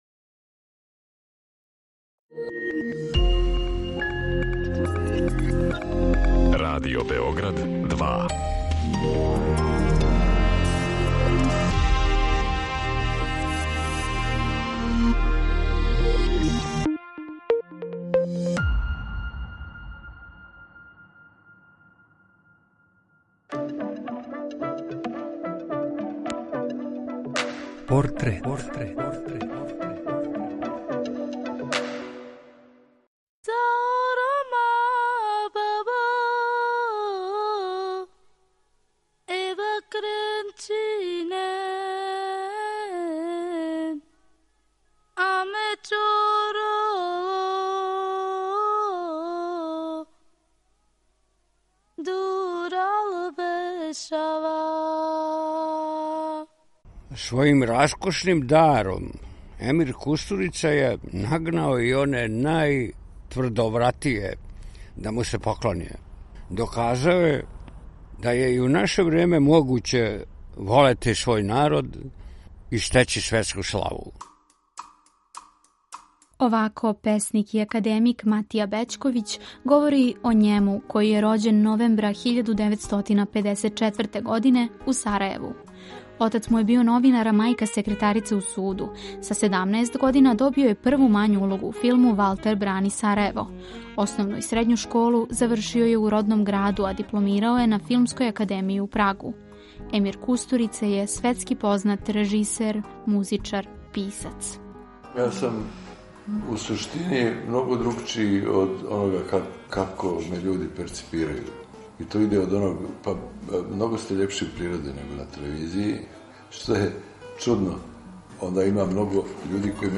Приче о ствараоцима, њиховим животима и делима испричане у новом креативном концепту, суптилним радиофонским ткањем сачињеним од: интервјуа, изјава, анкета и документраног материјала.
Чућете шта је Емир Kустурица говорио за нашу кућу, а о њему ће говорити његови пријатељи и сарадници - академик и песник Матија Бећковић и глумац Славко Штимац.